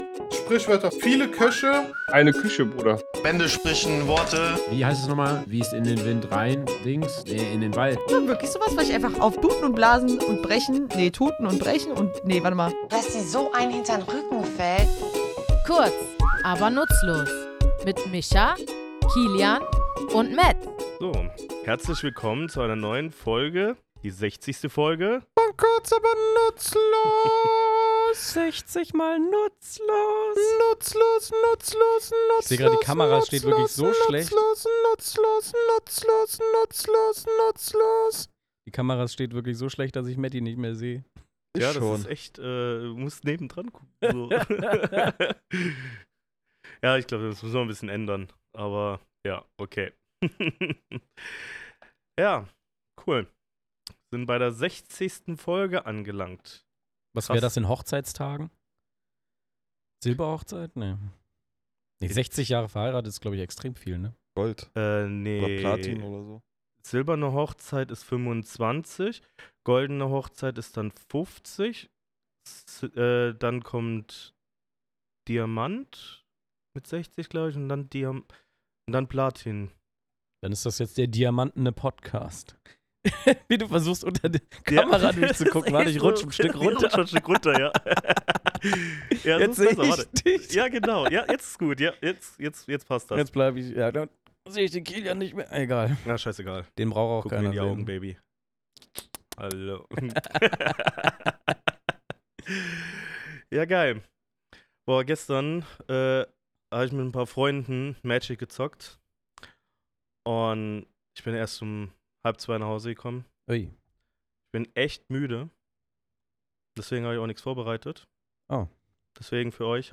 Aber steckt dahinter wirklich nur ein Fluch – oder vielleicht sogar ein gut gemeinter Wunsch? Wir, drei tätowierende Sprachliebhaber, klären in unserem Tattoostudio, warum „Scheiße“ im richtigen Kontext sogar Glück bringen kann, was Pferdeäpfel mit Theater zu tun haben – und warum man manchmal mehr meint, als man sagt.